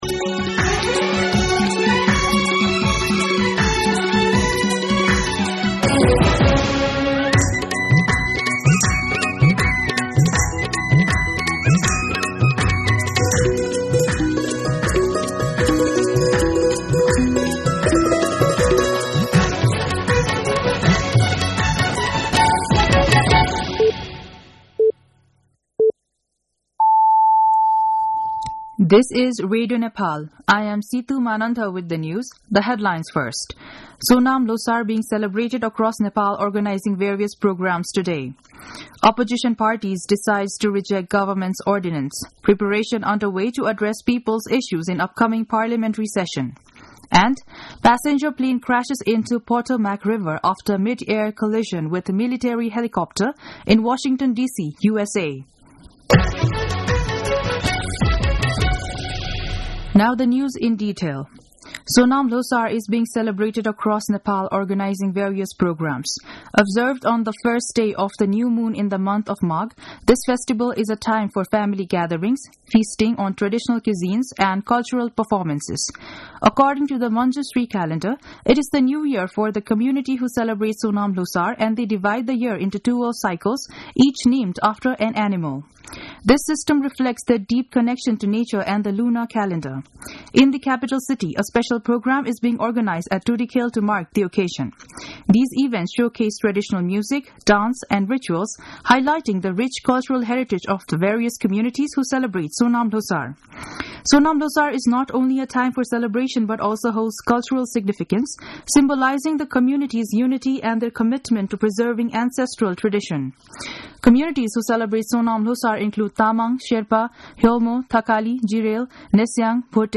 दिउँसो २ बजेको अङ्ग्रेजी समाचार : १८ माघ , २०८१